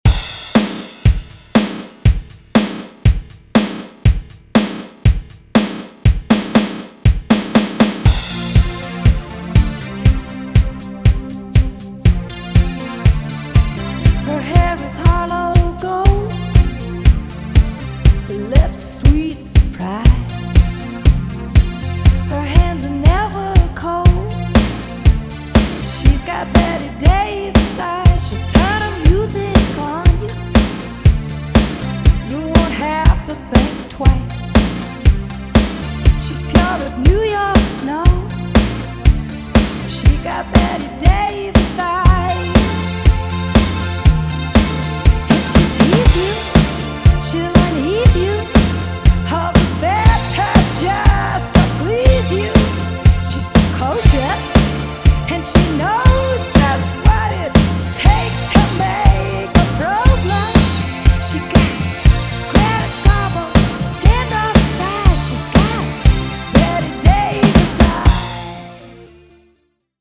mezcla la esencia latina con ritmos modernos